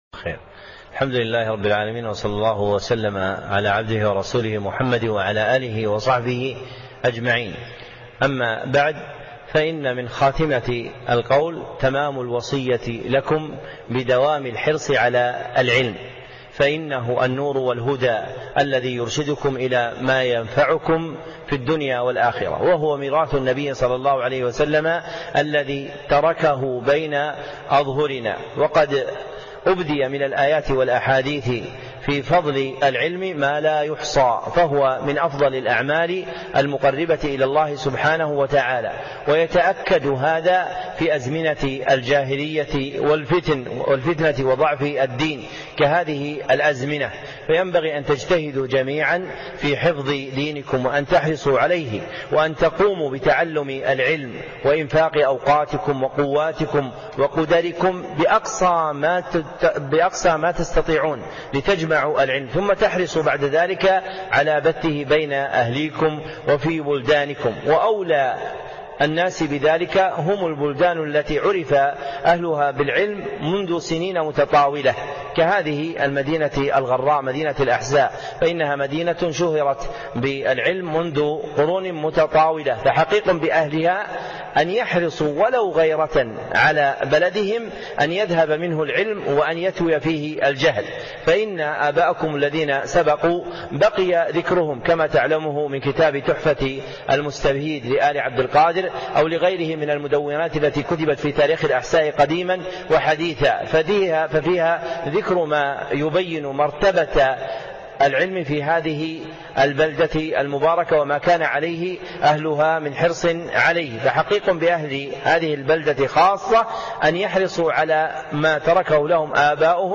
كلمة دوام الحرص على العلم